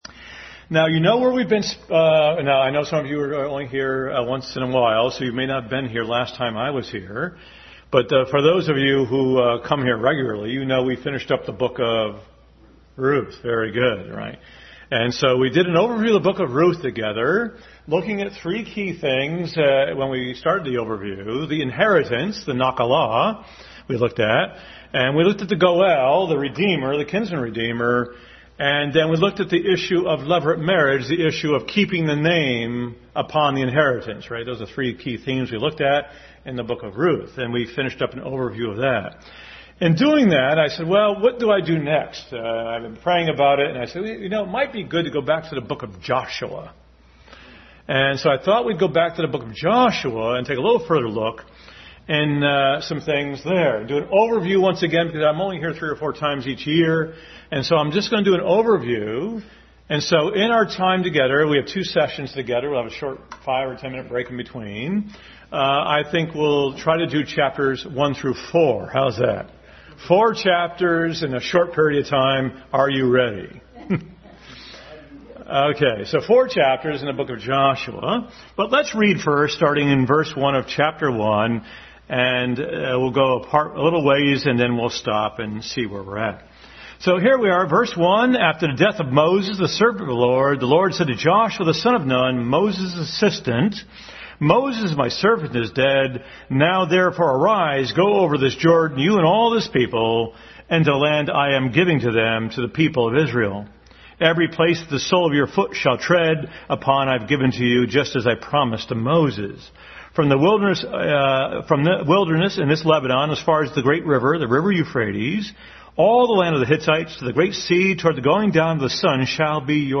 Adult Sunday School lesson.